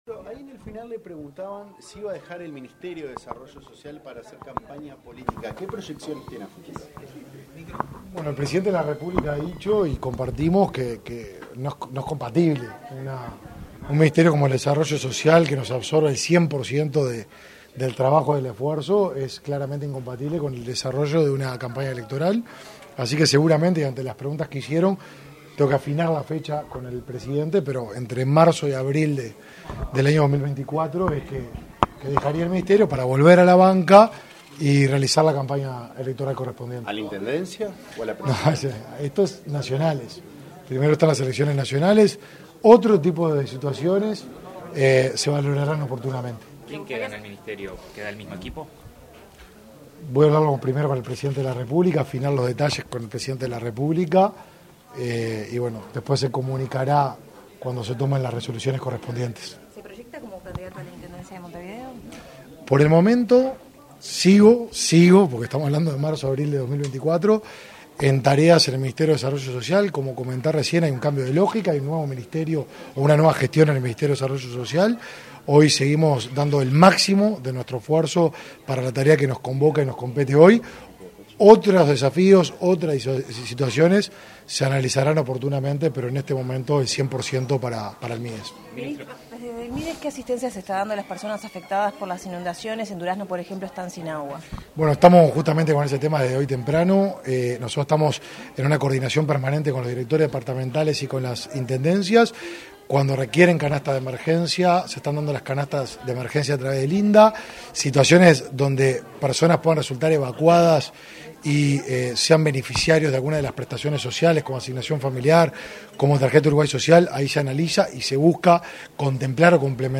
Declaraciones a la prensa del ministro del Mides, Martín Lema
Declaraciones a la prensa del ministro del Mides, Martín Lema 13/09/2023 Compartir Facebook X Copiar enlace WhatsApp LinkedIn Tras participar en el almuerzo de la Asociación de Dirigentes de Marketing del Uruguay (ADM), este 13 de setiembre, el titular del Ministerio de Desarrollo Social (Mides), Martín Lema, realizó declaraciones a la prensa.